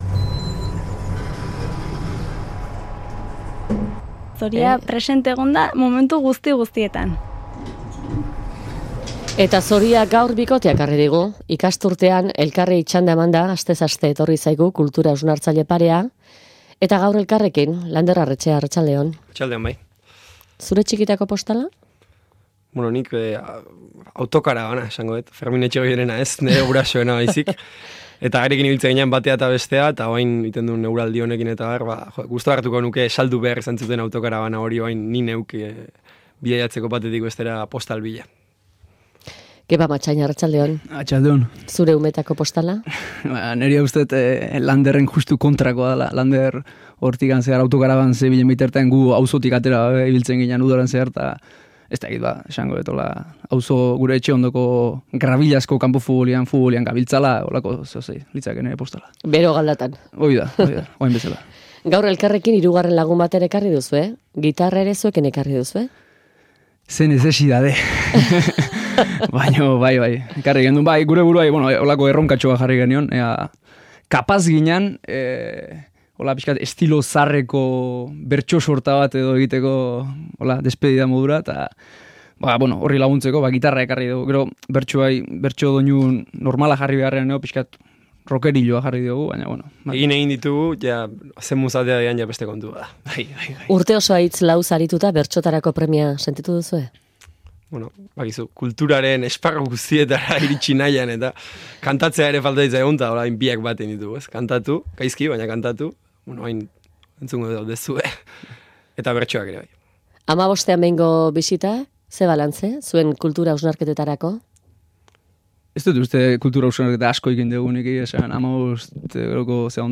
gitarraren laguntzaz hitz laukoa bertsotan emanda.
gitarra